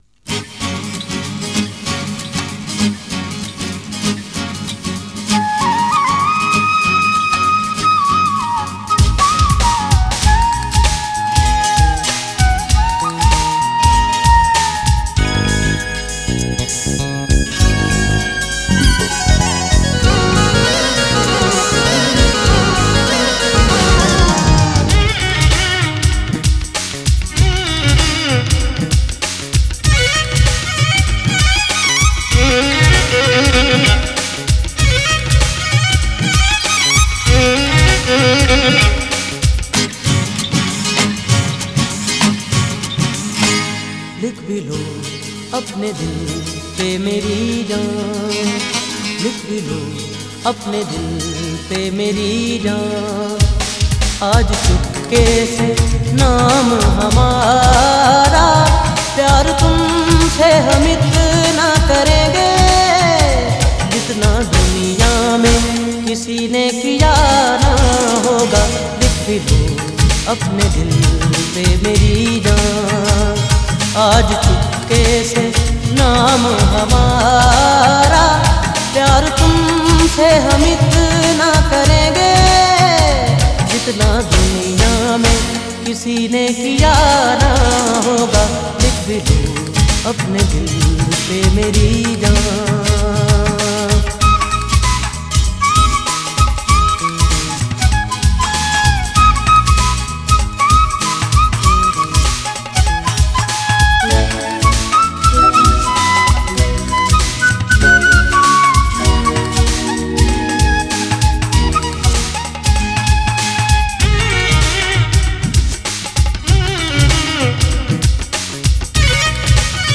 Punjabi Qawwali and Sufiana Kalam
Ashiqana Qawwali